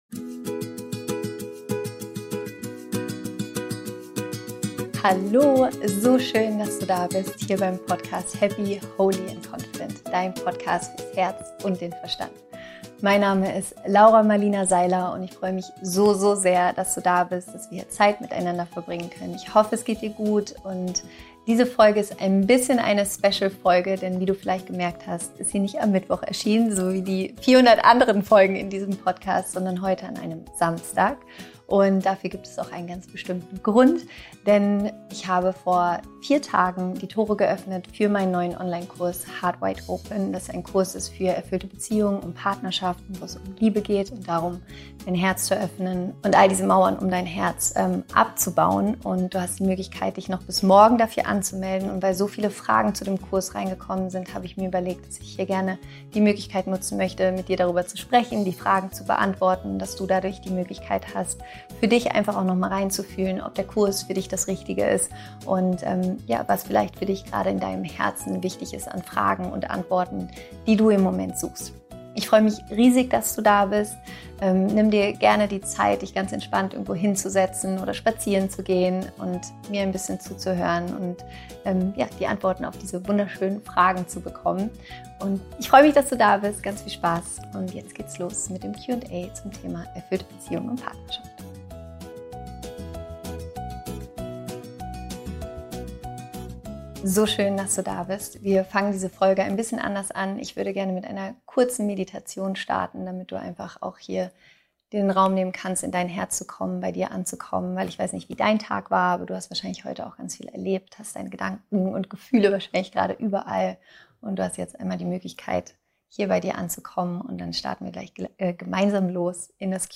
Und weil so viele Fragen von euch zu dem Kurs und allgemein immer wieder zum Thema Partnerschaft und Beziehungen gestellt wurden, habe ich eine neue Q&A Special Podcastfolge aufgenommen, in der ich eure meist gestellten Fragen beantworte.
Außerdem wartet auf dich in dieser Folge eine kurze Meditation, die dir dabei hilft, dich mit deinem Herzen zu verbinden und bei dir anzukommen.